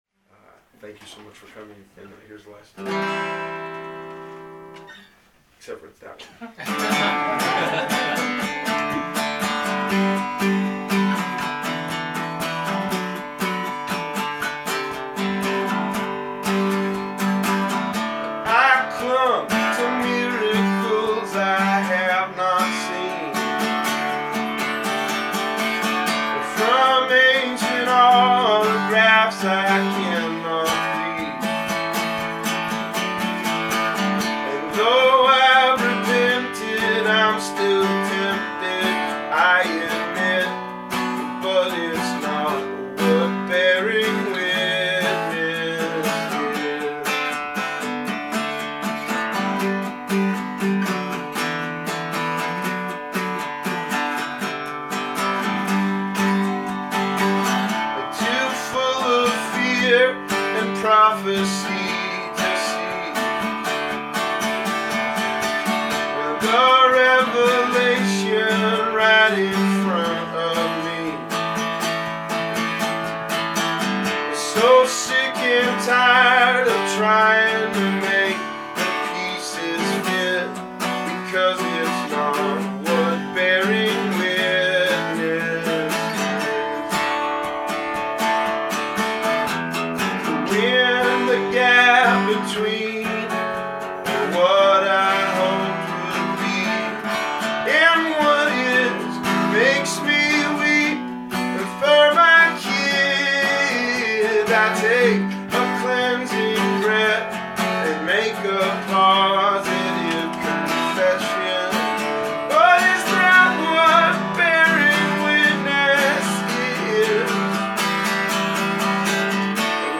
House Show Setlist